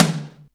• Small Reverb Tom Drum D Key 24.wav
Royality free tom drum tuned to the D note. Loudest frequency: 2213Hz
small-reverb-tom-drum-d-key-24-5E1.wav